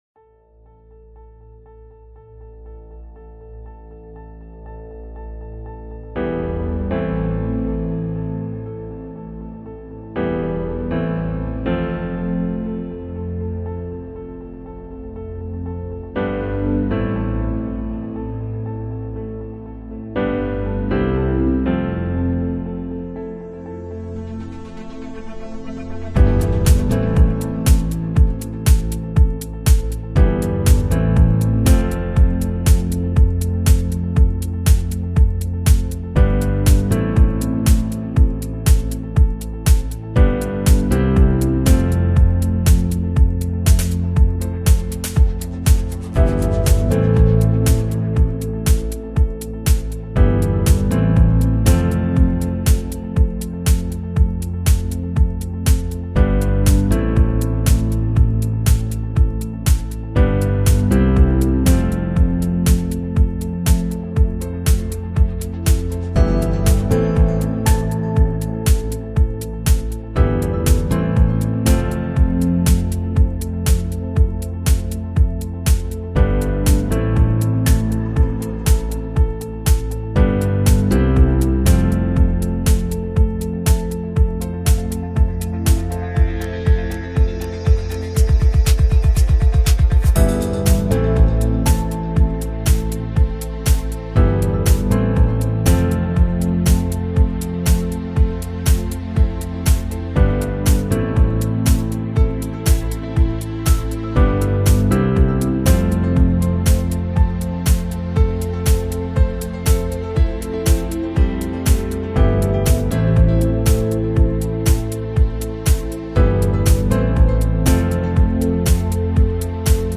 ✨ Un témoignage bouleversant et inspirant, qui prouve que la guérison est possible, même après des décennies de souffrance.